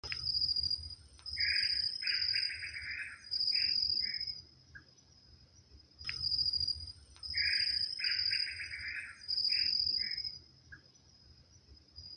Tataupá Común (Crypturellus tataupa)
Nombre en inglés: Tataupa Tinamou
Localidad o área protegida: Parque Provincial Teyú Cuaré
Condición: Silvestre
Certeza: Vocalización Grabada
tataupa.mp3